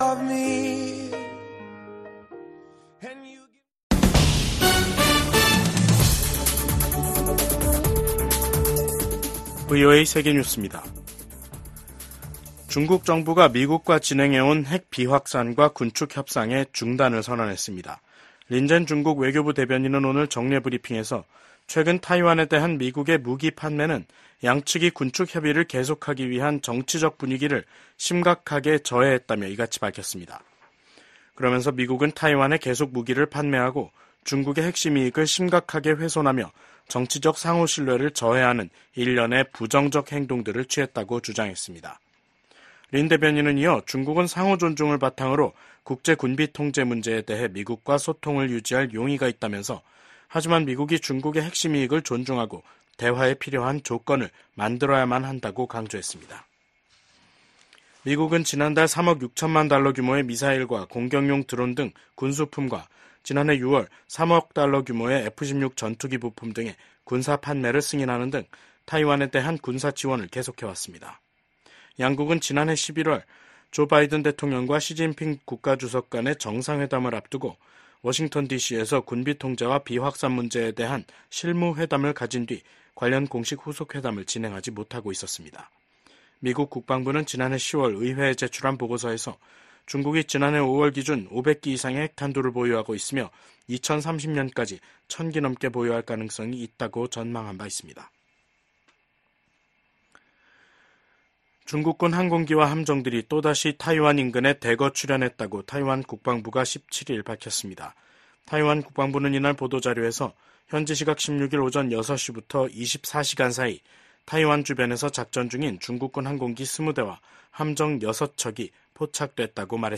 VOA 한국어 간판 뉴스 프로그램 '뉴스 투데이', 2024년 7월 17일 3부 방송입니다. 미 국방부의 고위 관리가 VOA와의 단독 인터뷰에서 미한 양국은 핵을 기반으로 한 동맹이라고 밝혔습니다. 북한의 엘리트 계층인 외교관들의 한국 망명이 이어지고 있습니다. 북한에서 강제노동이 광범위하게 제도화돼 있으며 일부는 반인도 범죄인 노예화에 해당할 수 있다고 유엔이 지적했습니다.